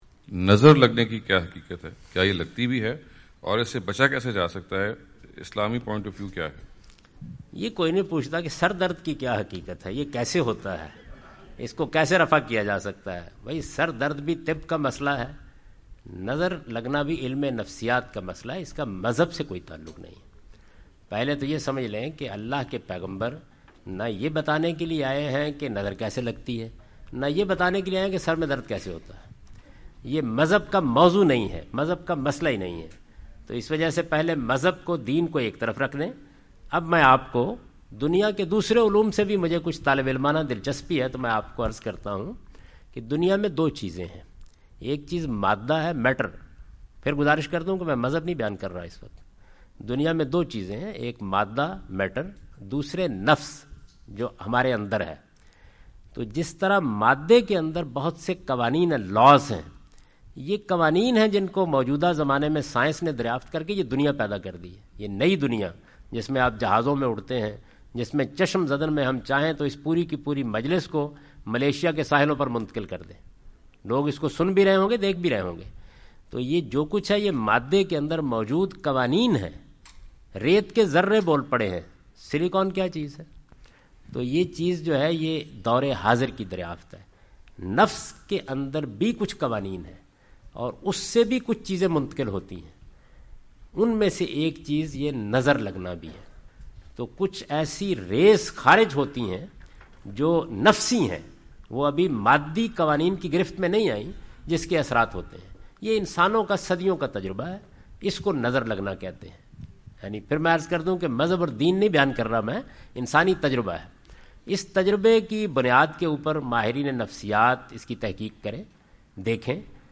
Category: English Subtitled / Questions_Answers /
Javed Ahmad Ghamidi answer the question about "Reality of Evil Eye" during his US visit.
جاوید احمد غامدی اپنے دورہ امریکہ کے دوران ڈیلس۔ ٹیکساس میں "نظر بد کی حقیقت" سے متعلق ایک سوال کا جواب دے رہے ہیں۔